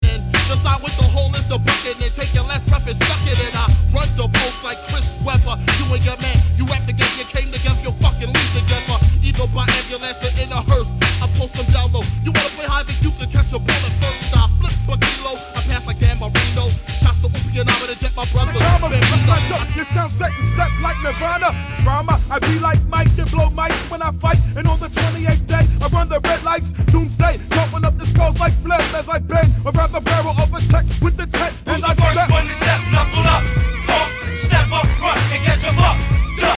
hardcore hip-hop